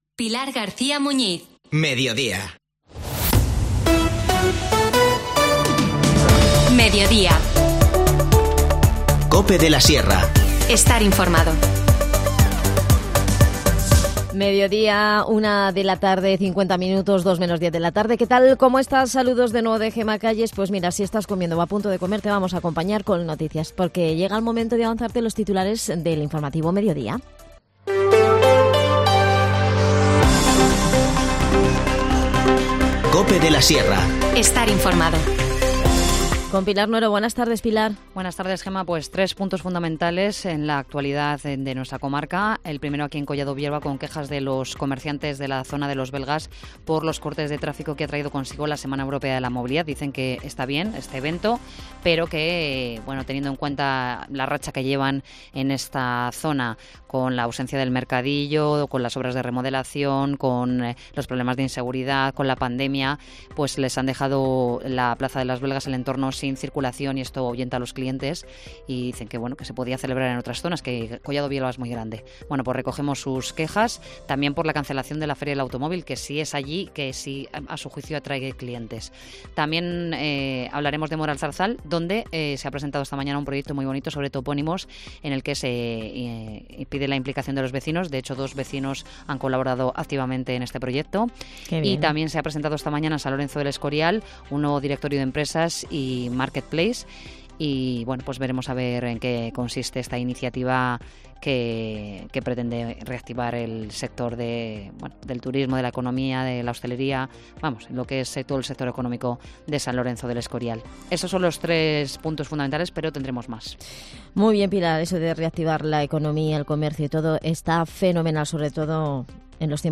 Nos cuenta todos los detalles Santos Esteban, concejal de Desarrollo Local y Comercio.